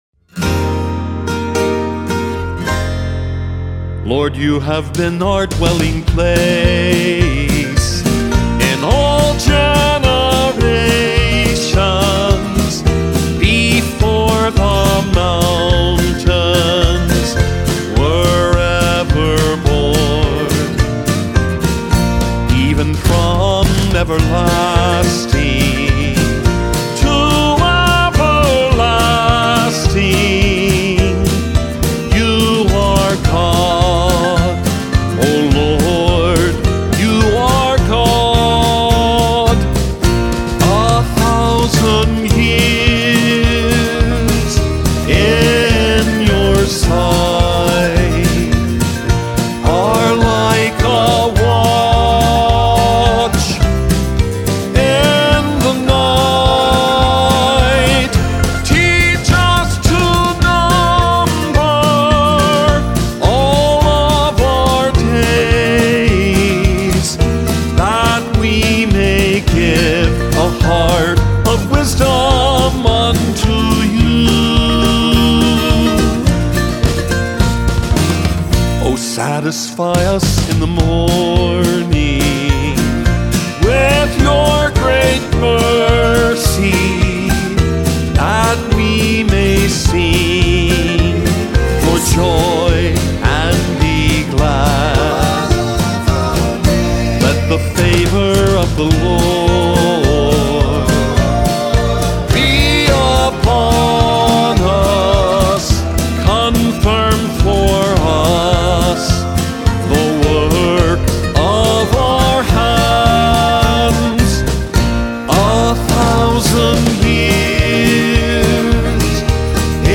NoLeadVocal   Psalm 90 Folk God’s Eternity and the Brevity of Human Life. Guitars, Balalaika